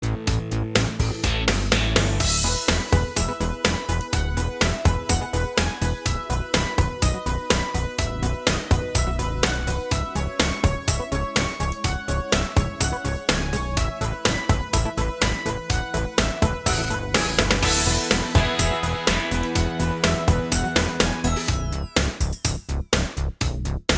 Minus All Guitars Pop (1980s) 4:07 Buy £1.50